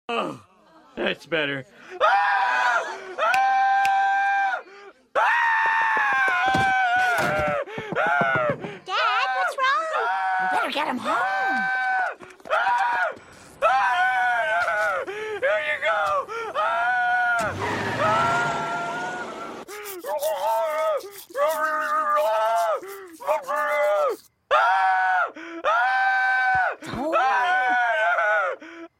Komik